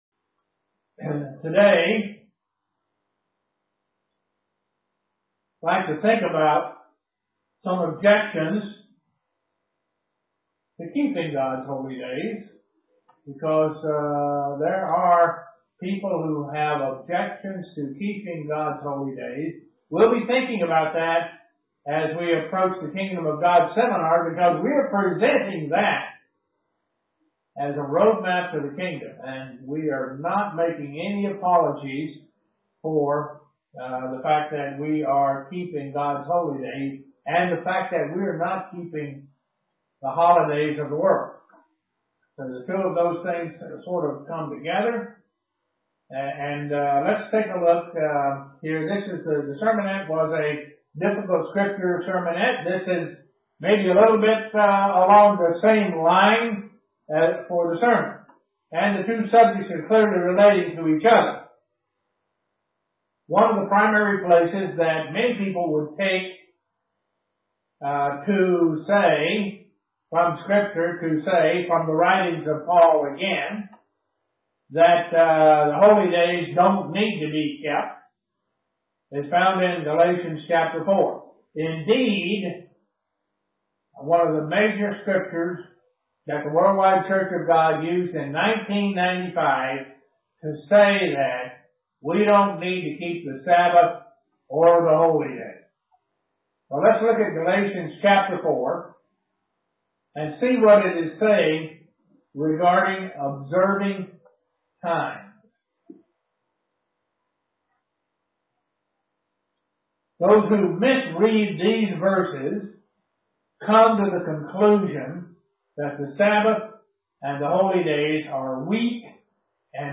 Given in Elmira, NY
Print What are the objections for not keeping God's Holy Days UCG Sermon Studying the bible?